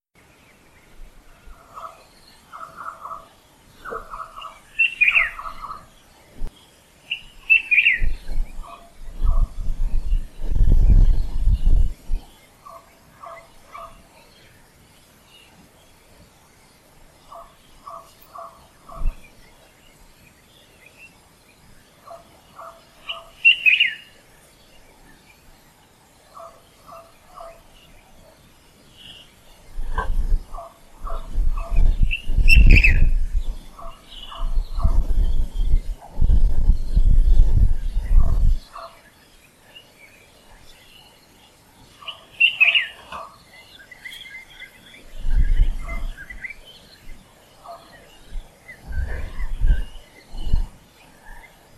Rebero-Kigali birds noon 20th June 2015
03_birds21june.mp3